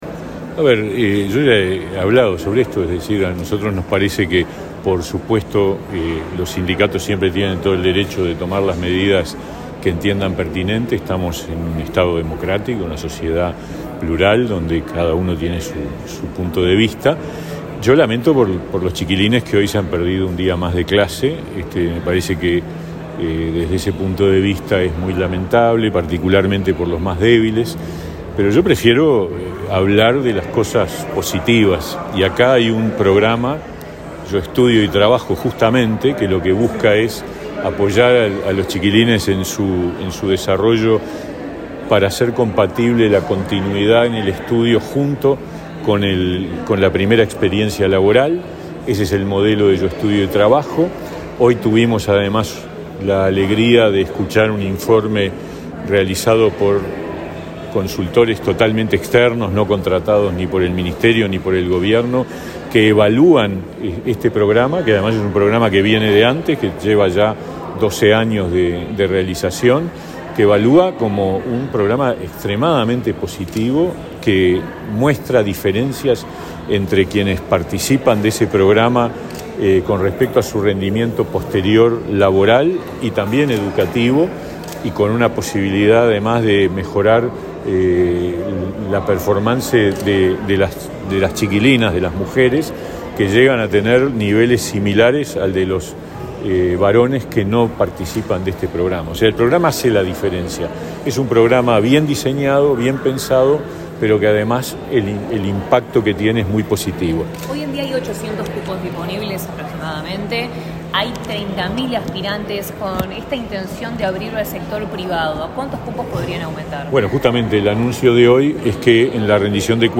Declaraciones del ministro de Trabajo, Pablo Mieres
Luego dialogó con la prensa.